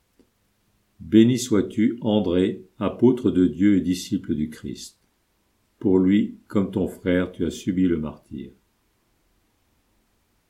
Antienne-Marie-Beni-sois-tu-Andre.mp3